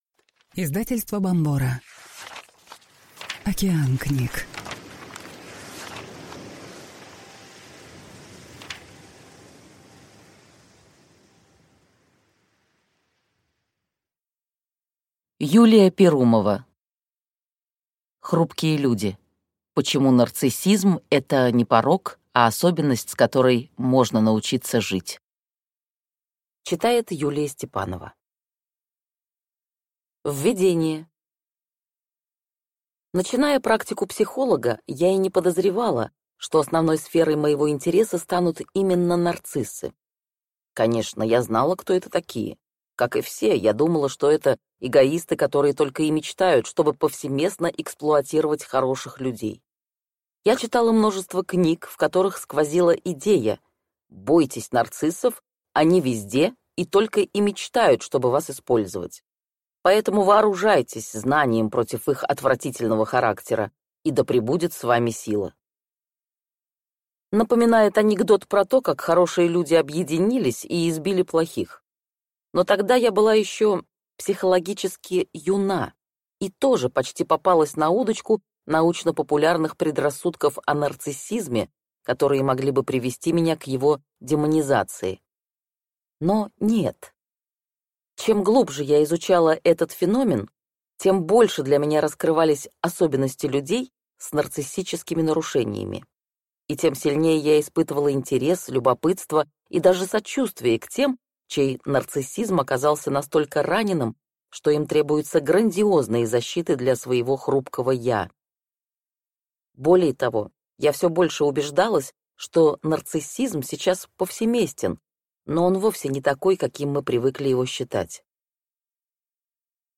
Аудиокнига Хрупкие люди. Почему нарциссизм – это не порок, а особенность, с которой можно научиться жить | Библиотека аудиокниг